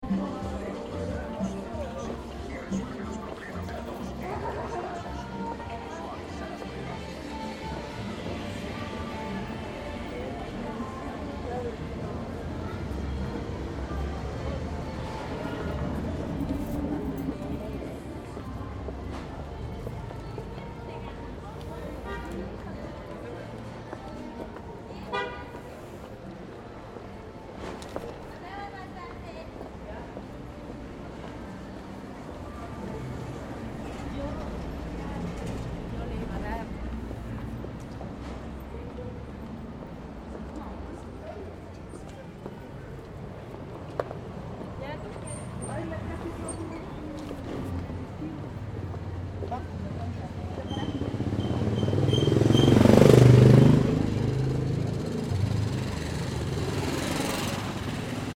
Promenons-nous dans les rues et imprégnons-nous de l’ambiance sonore :
LPcalle2.mp3